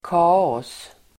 Uttal: [k'a:ås]